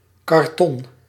Ääntäminen
Synonyymit pasteboard Ääntäminen US UK : IPA : /ˈkɑːdbɔːd/ US : IPA : /ˈkɑɹdbɔɹd/ Haettu sana löytyi näillä lähdekielillä: englanti Käännös Ääninäyte Substantiivit 1. karton Määritelmät Substantiivit A wood - based material resembling heavy paper , used in the manufacture of boxes , cartons and signs .